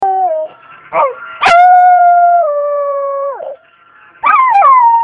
Puppy Howl Sound Effect Download: Instant Soundboard Button